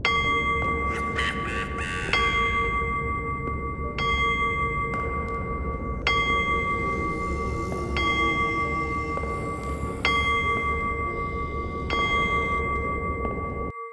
cuckoo-clock-07.wav